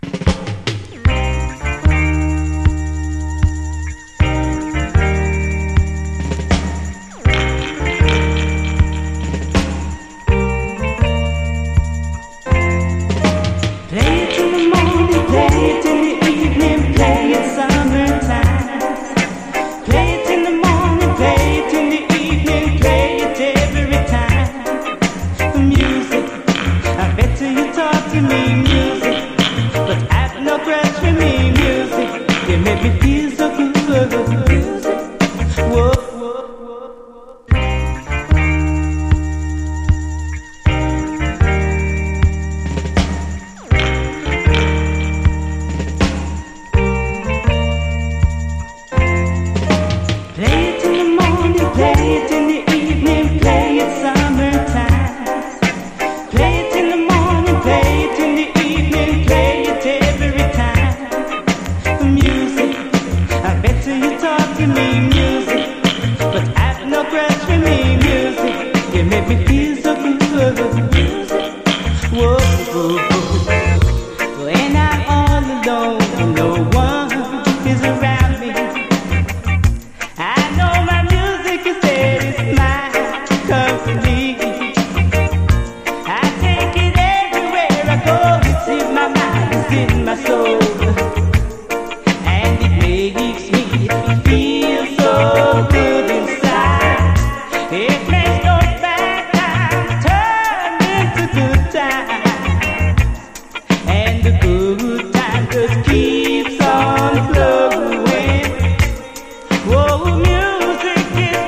REGGAE
フワフワとダビーな浮遊感のマイナー・ラヴァーズ！
フンワリとダビーな浮遊感のドリーミー・ラヴァーズ！後半にダブも収録。